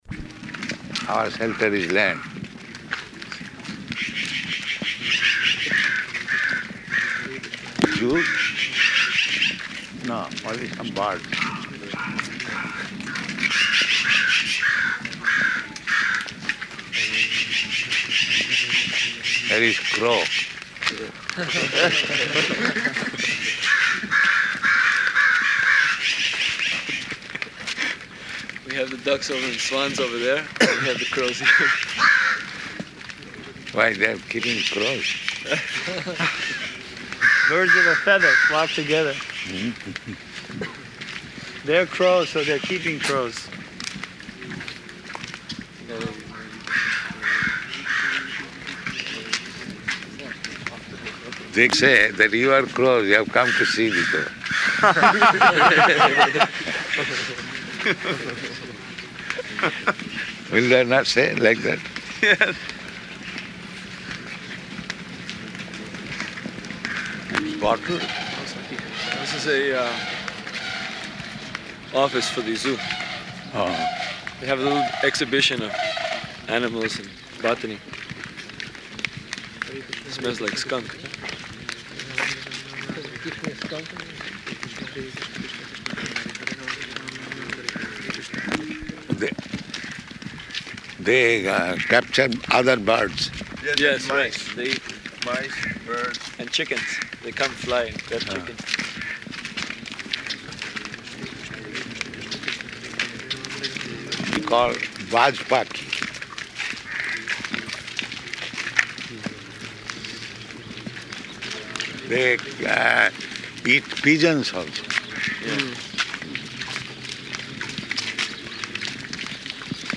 Morning Walk --:-- --:-- Type: Walk Dated: July 20th 1975 Location: San Francisco Audio file: 750720MW.SF.mp3 Prabhupāda: ...our shelter is land.
[several birds are making noises, including a crow] There is crow.